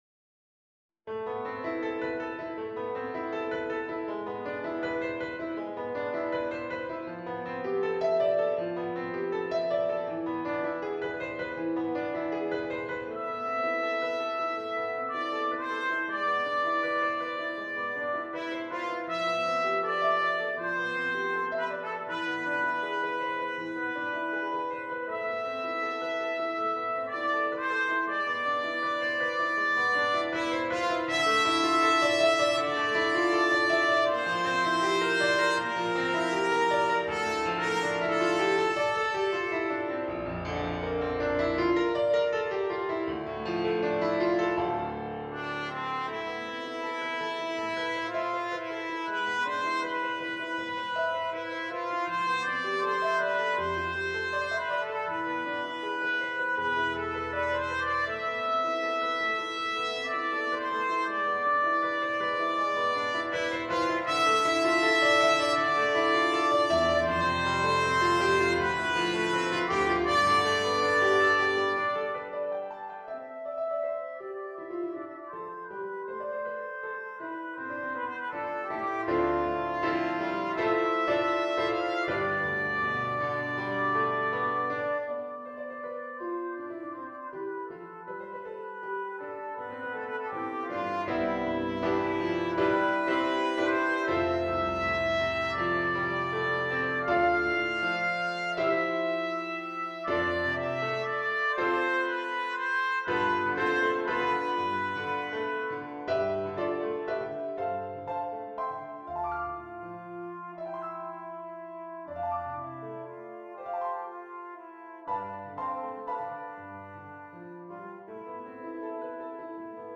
Trumpet  version
• Piano score
• Reference mp3 Trumpet version